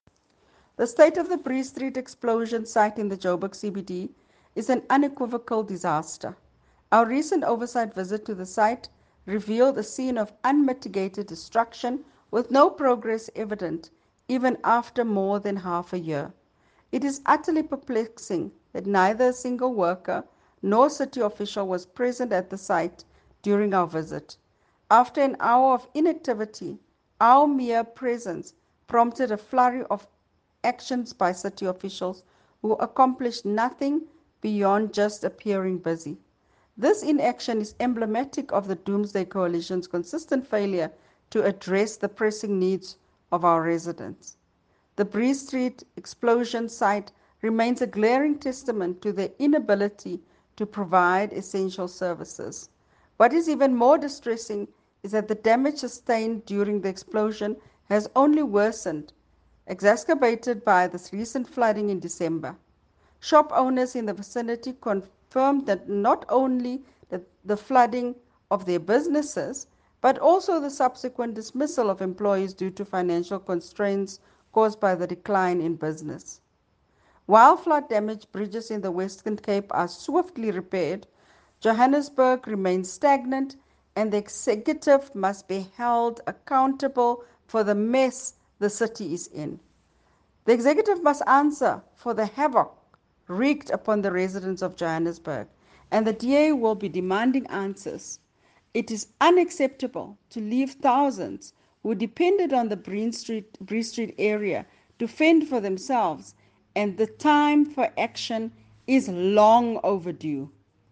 Issued by Cllr Belinda Kayser-Echeozonjoku – DA Johannesburg Caucus Leader
Note to Editors: Please find a soundbite
Belinda-English-JHB-CBD-Explosion.mp3